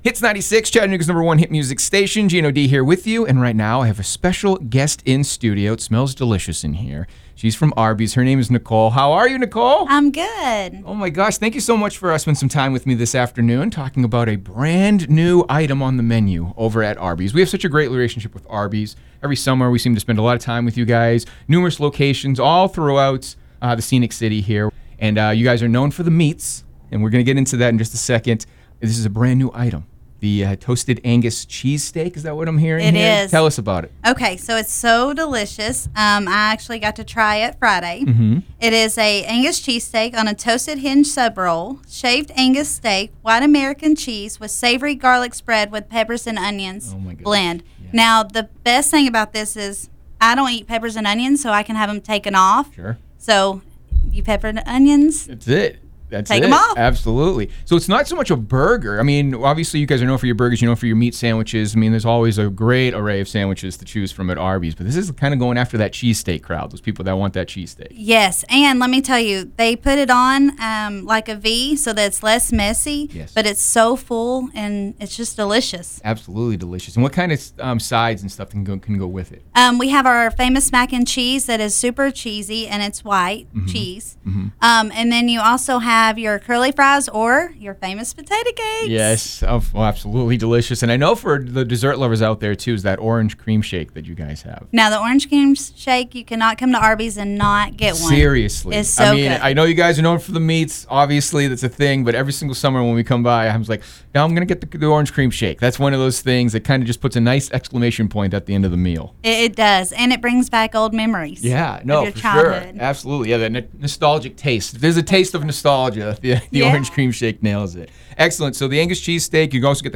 stopped by the studio